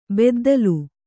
♪ beddalu